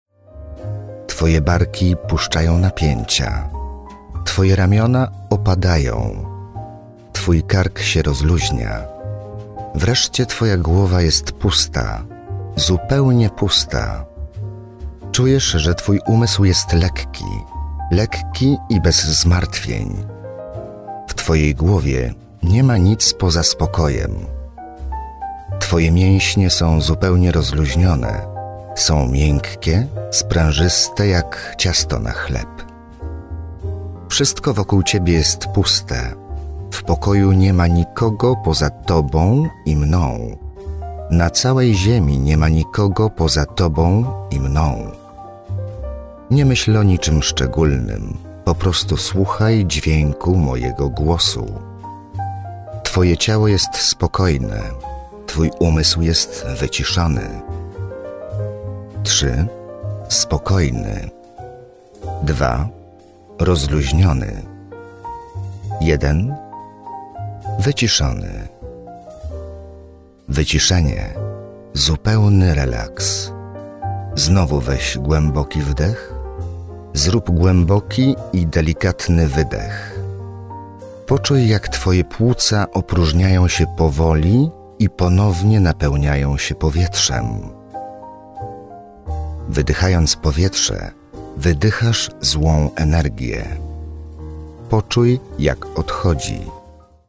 warm versatile Polish voice
Male 30-50 lat
Nagranie lektorskie